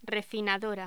Locución: Refinadora
voz
Sonidos: Voz humana